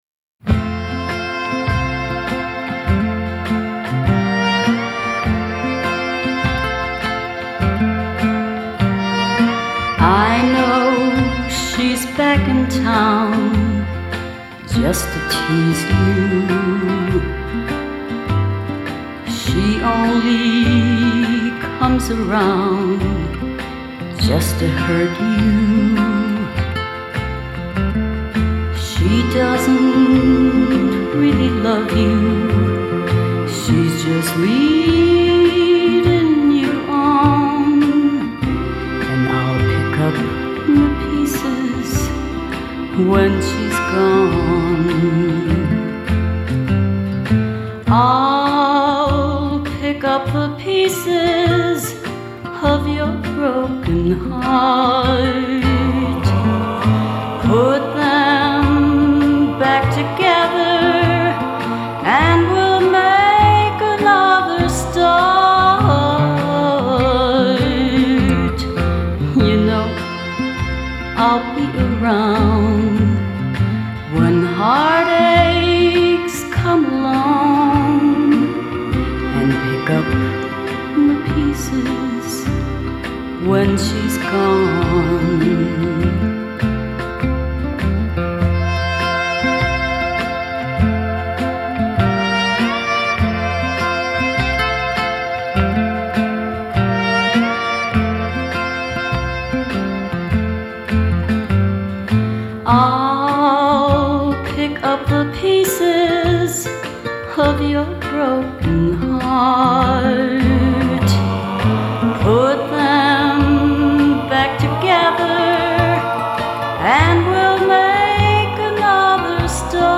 a Country singer from Chicago